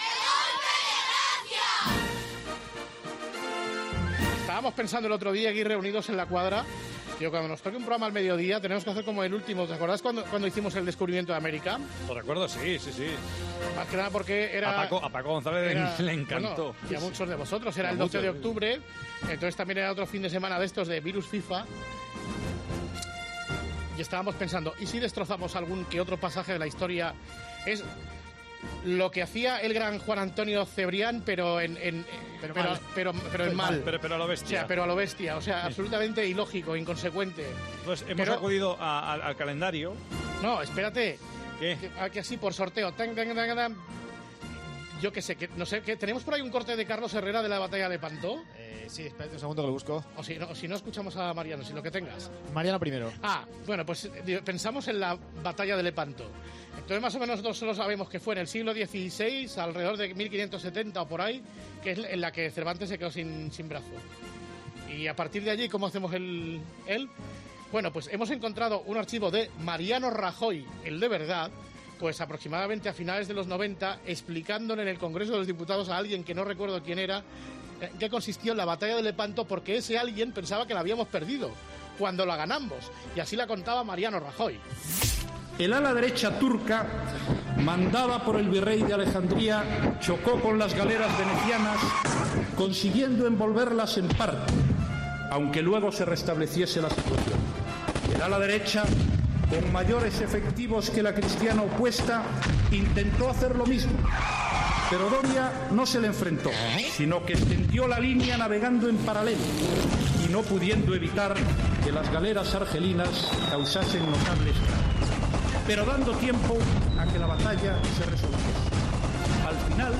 Pues después de recordar ese fragmento de hemeroteca, El Grupo Risa tira de sus enviados especiales, de sus técnicos, de Jose María García y de Javier Ares para recrear en una reconstrucción lo que ellos creen que debió ser, más o menos, la Batalla de Lepanto, con el sonido característico de las retransmisiones de la Vuelta Ciclista a España Vintage y los personajes habituales.